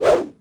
footswing1.wav